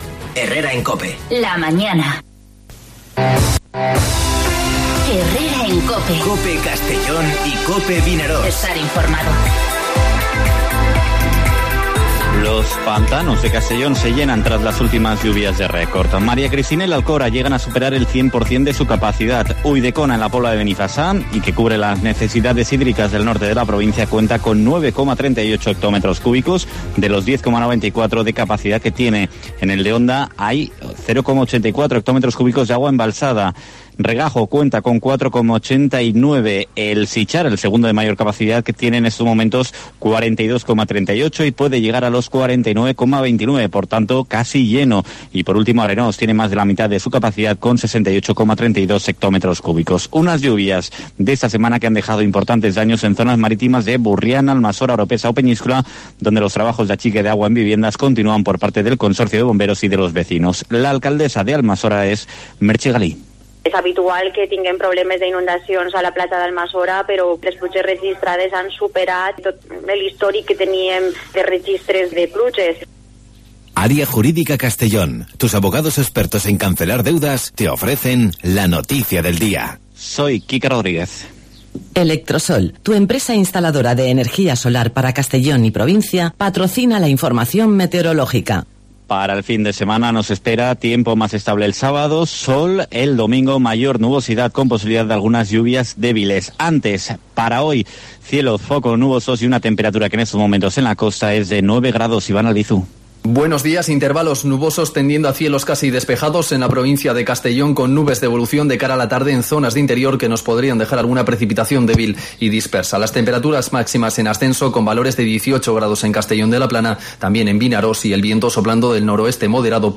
Informativo Herrera en COPE en la provincia de Castellón (03/04/2020)